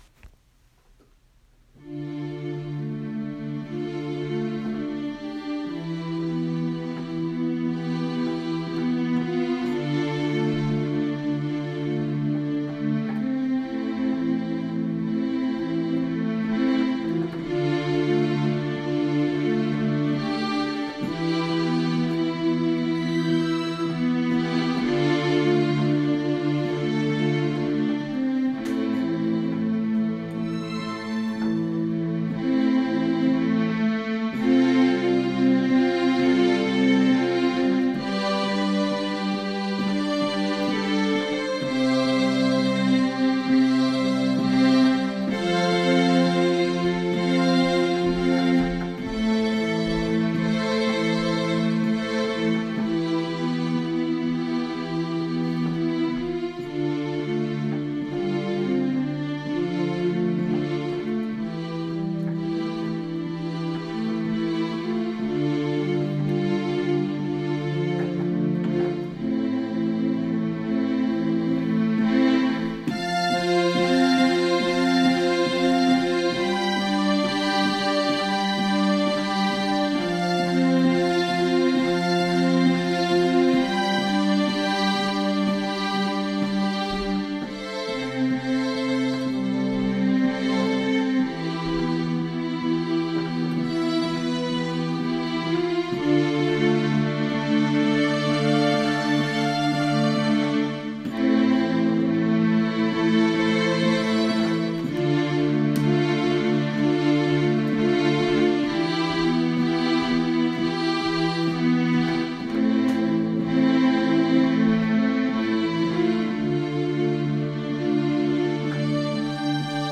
melancholy sounds